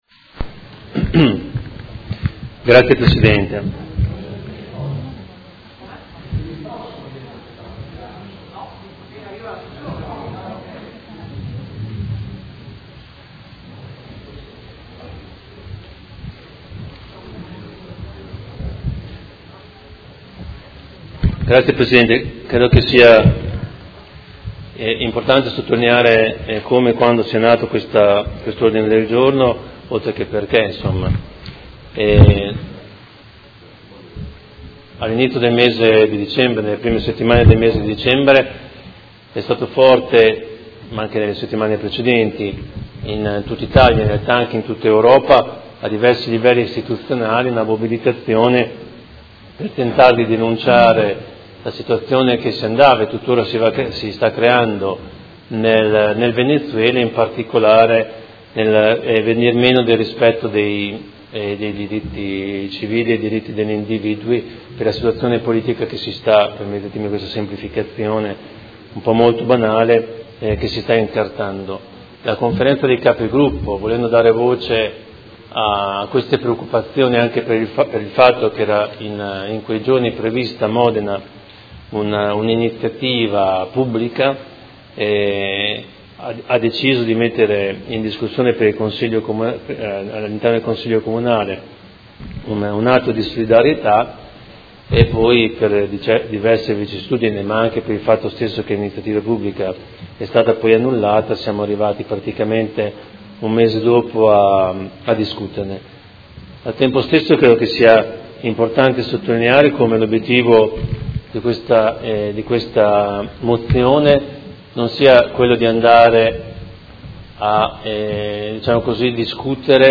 Seduta del 25/01/2018. Ordine del Giorno presentato dal Consigliere Poggi (PD) avente per oggetto: Solidarietà al Popolo Venezuelano